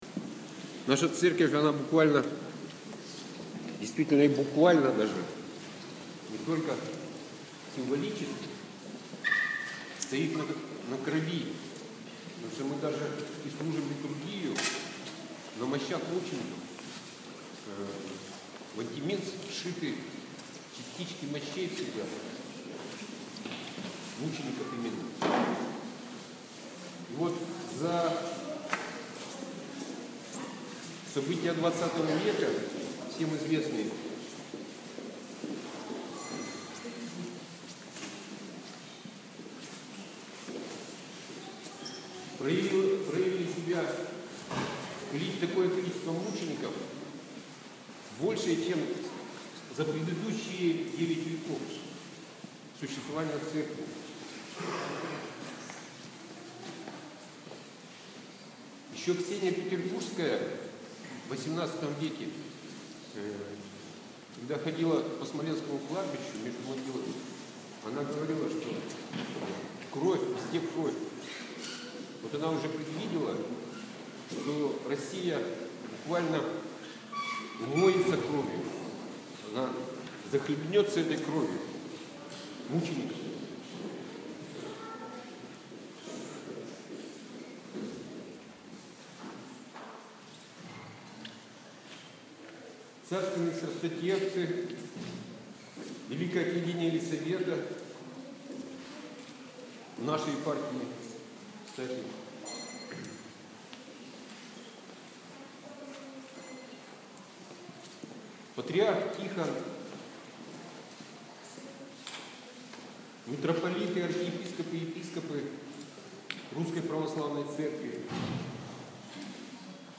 Слово